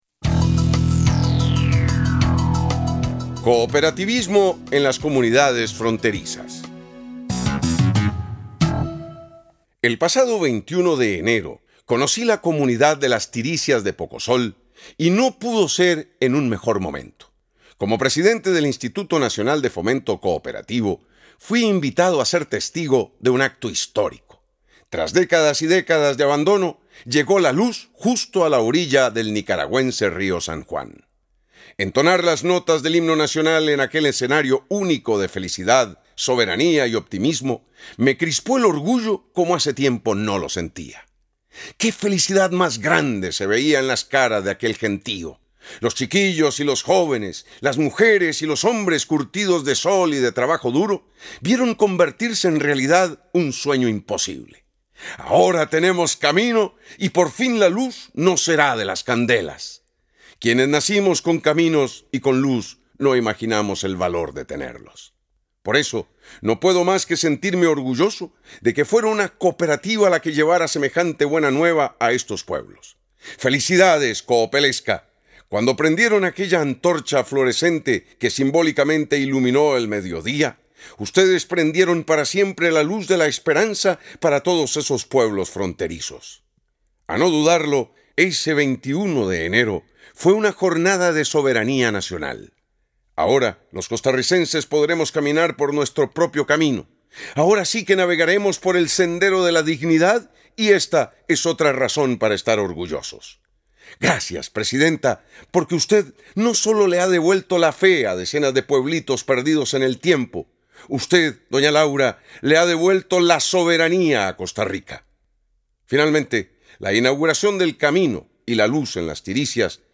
DESCARGAR AUDIO Freddy González Rojas. Presidente de INFOCOOP
Lunes, 06 Febrero 2012 02:51 Cooperativismo en las comunidades fronterizas Comentarista Invitado DESCARGAR AUDIO Freddy González Rojas.